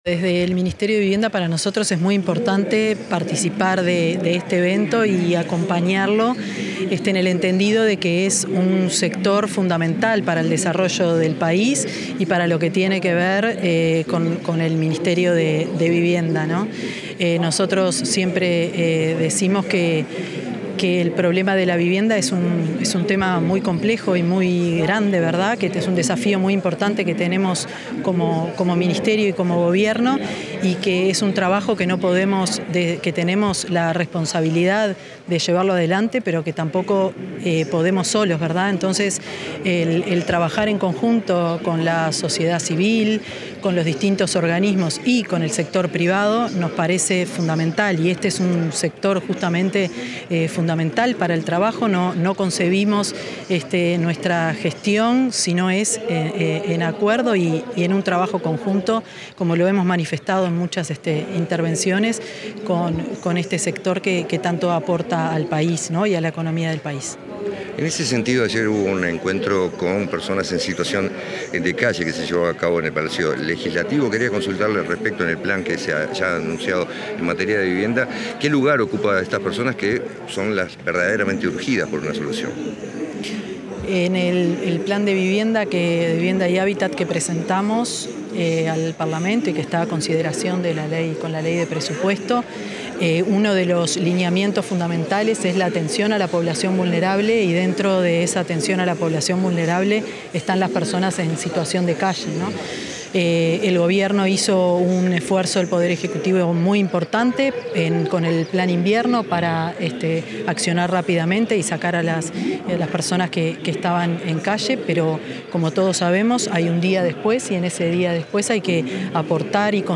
Declaraciones de la ministra de Vivienda, Tamara Paseyro
La ministra de Vivienda, Tamara Paseyro, dialogó con los medios informativos antes de participar en la inauguración de la Feria de la Construcción